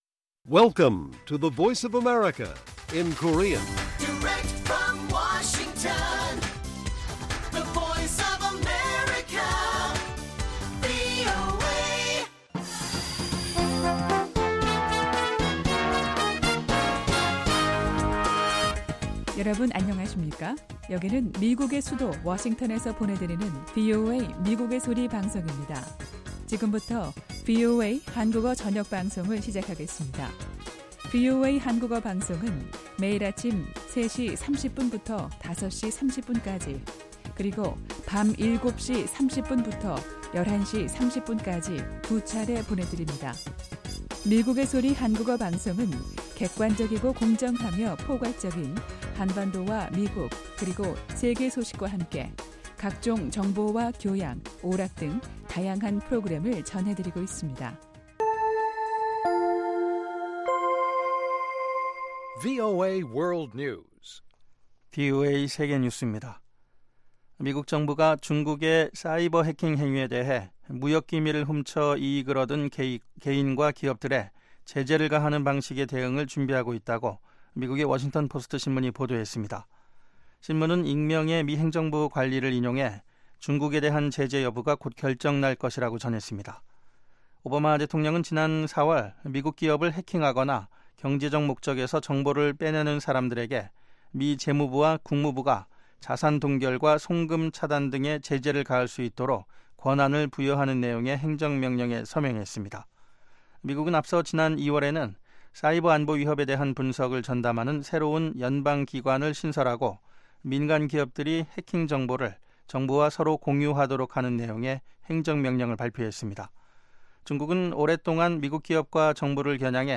VOA 한국어 방송의 간판 뉴스 프로그램 '뉴스 투데이' 1부입니다. 한반도 시간 매일 오후 8시부터 9시까지 방송됩니다.